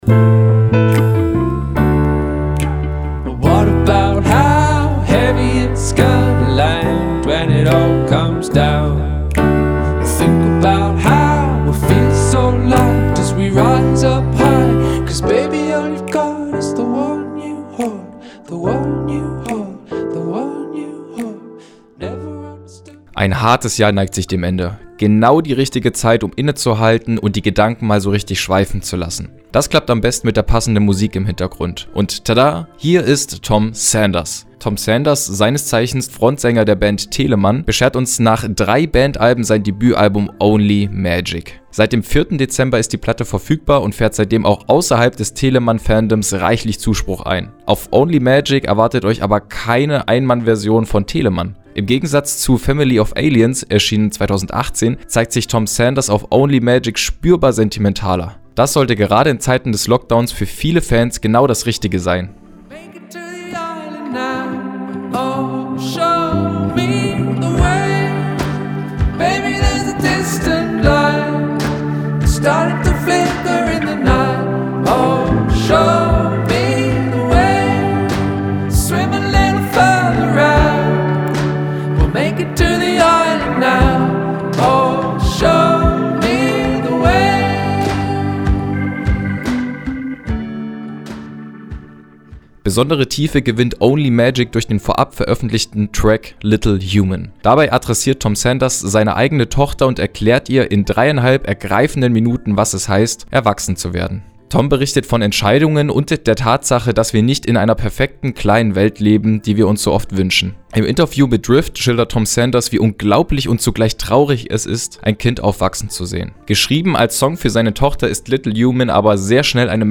Das klappt am besten mit der passenden Musik im Hintergrund.